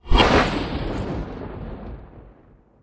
khanat-sounds-sources - Source files to create all the .wav used in the Khanat game
archmage_attack22.wav